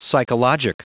Prononciation du mot psychologic en anglais (fichier audio)
Prononciation du mot : psychologic